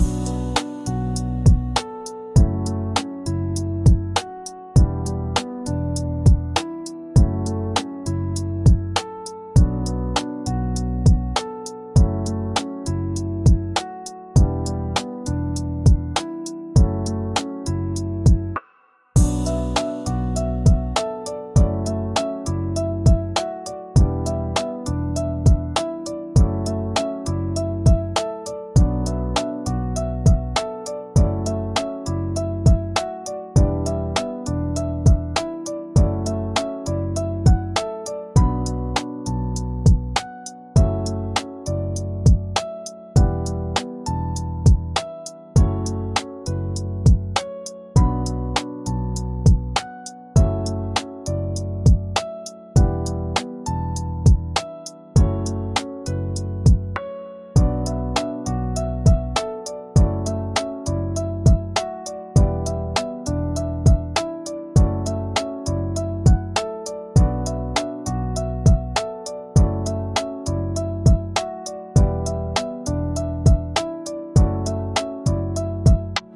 B Minor – 100 BPM
Electronic
Hip-hop
Pop
Romantic